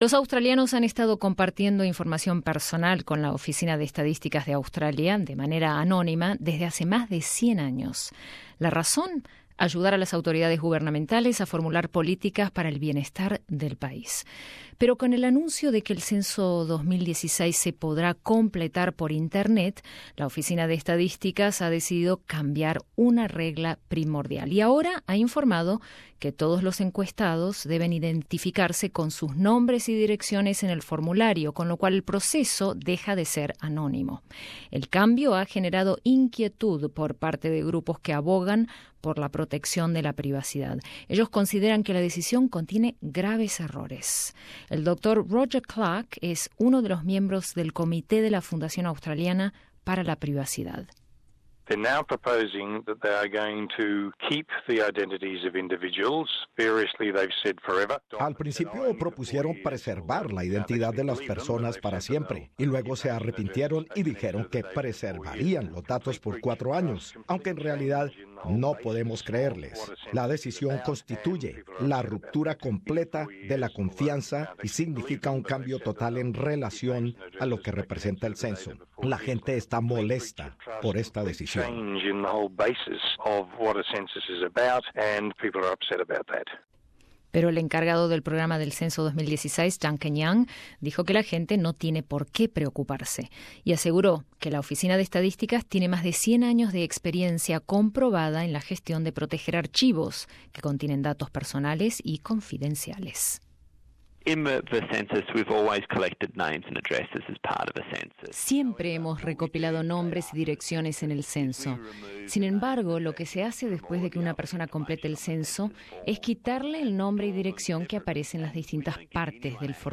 El cambio ha generado inquietud por parte de grupos que abogan por la protección de la privacidad debido a que consideran que la decisión podría tener un grave impacto. Escucha aquí nuestro informe.